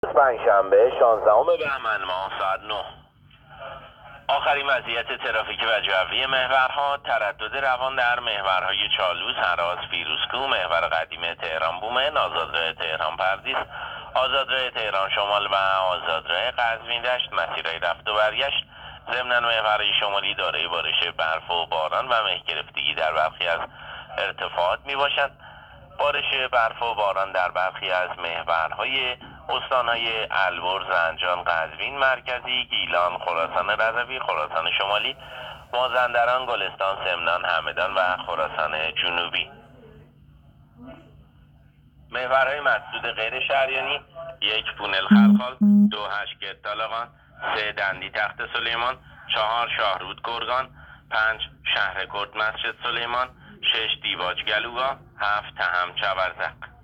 گزارش رادیو اینترنتی از آخرین وضعیت ترافیکی جاده‌ها ساعت ۹ شانزدهم بهمن؛